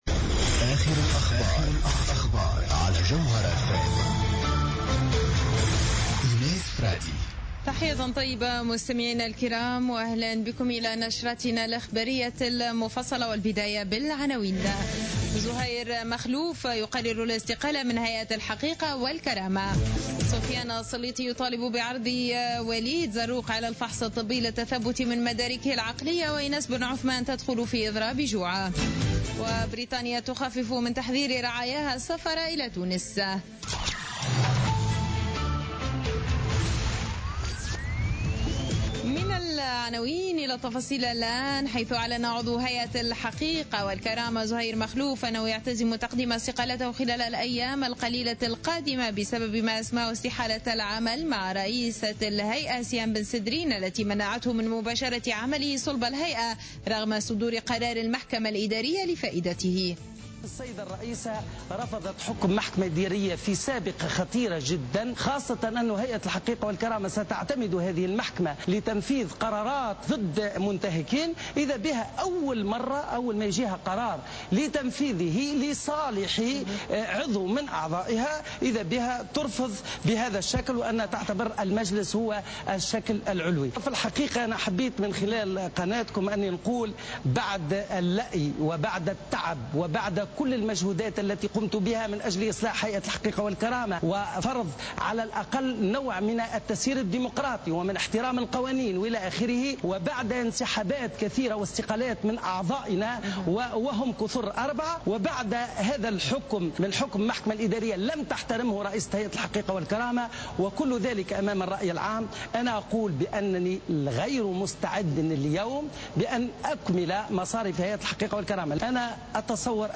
نشرة أخبار منتصف الليل ليوم الخميس 8 أكتوبر 2015